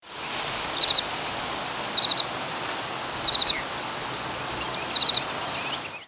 Common Yellowthroat diurnal flight calls
Perched bird with cricket song and Carolina Wren calling in the background.